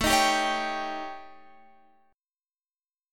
A7b5 chord